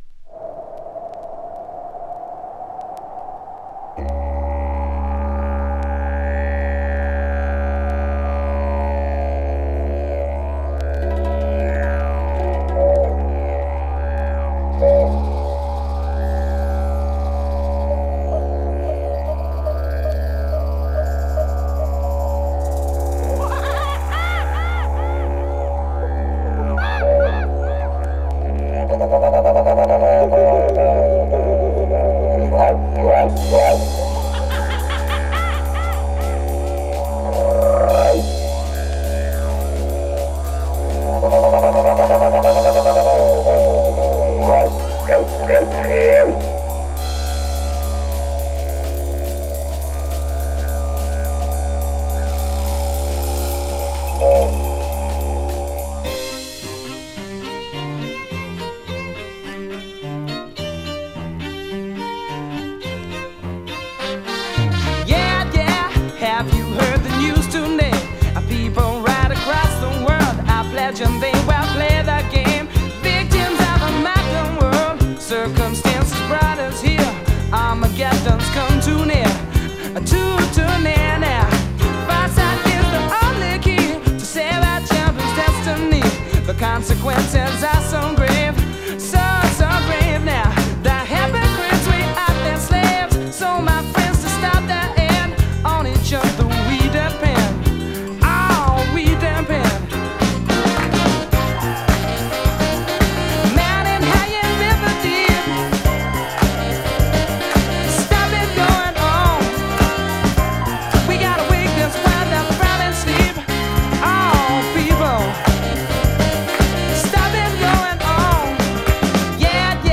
> CROSSOVER/ACID JAZZ/CLUB JAZZ